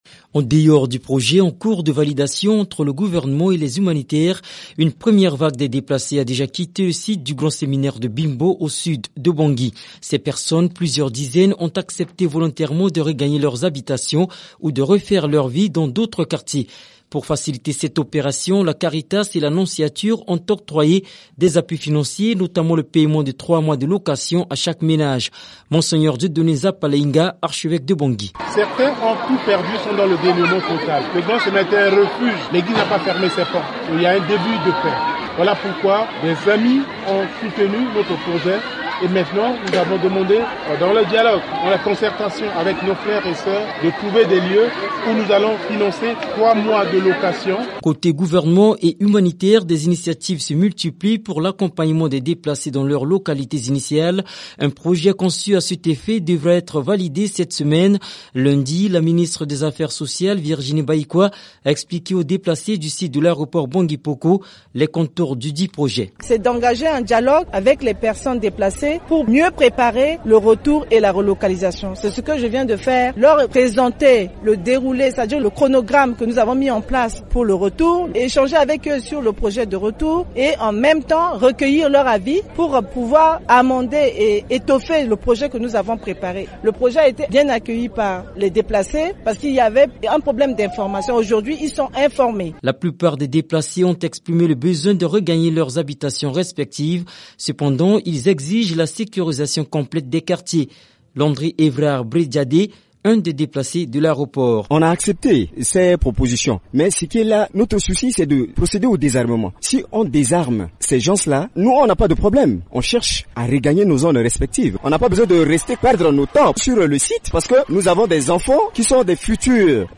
Début du retour des déplacés à leur domicile à Bangui, reportage